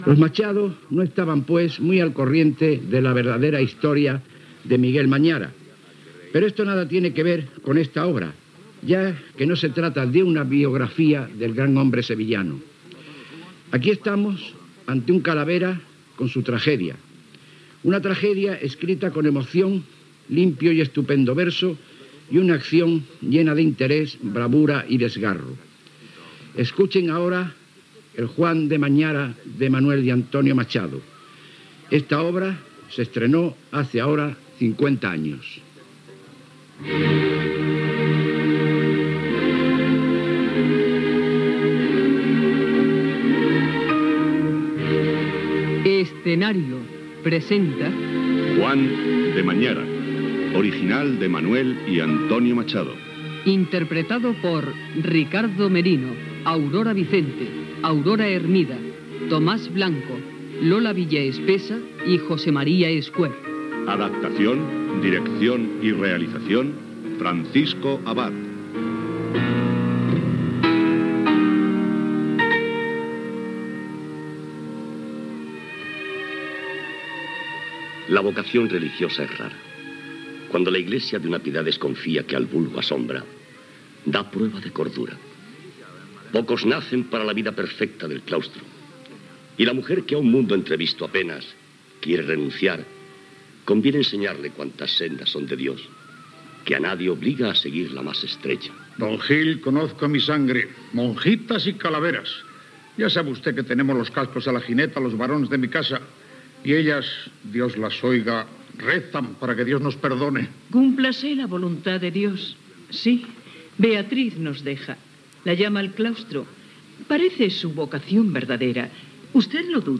Adaptacio radiofònica de "Juan de Mañara", de Manuel Machado i Antonio Machado.
Ficció